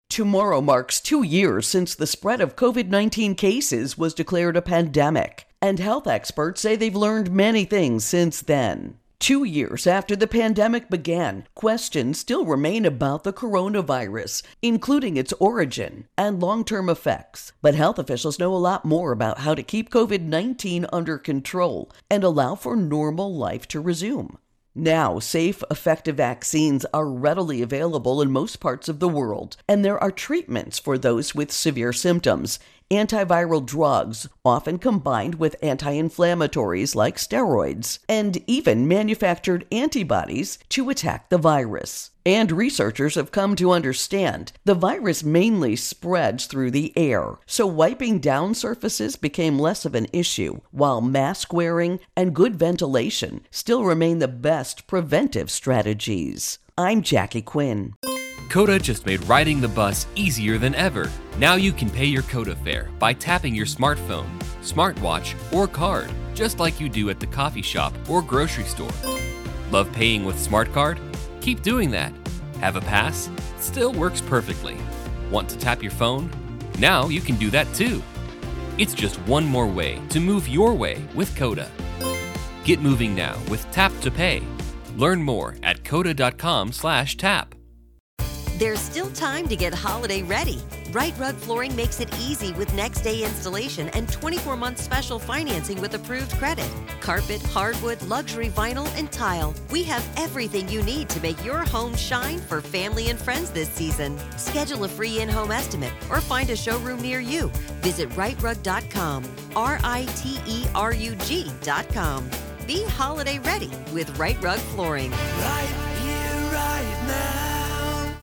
Viral Questions Pandemic Changes Two Years Intro and Voicer